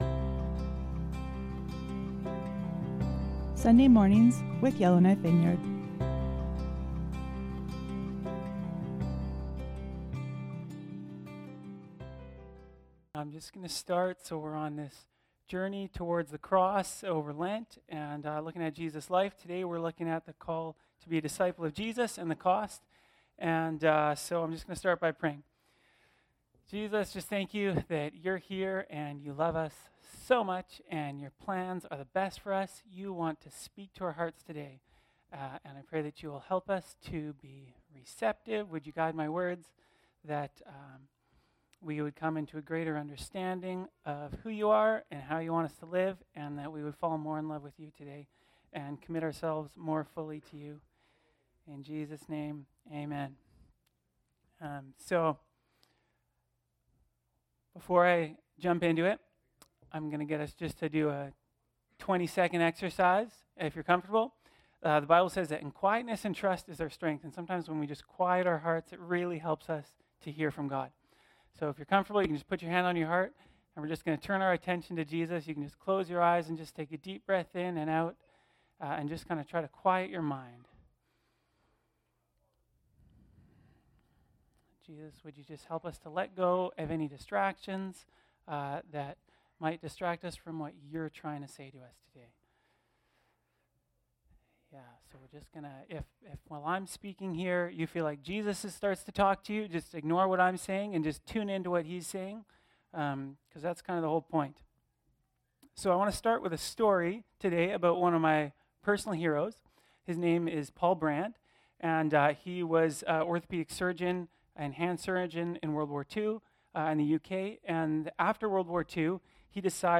Sermons | Yellowknife Vineyard Christian Fellowship
Guest Speaker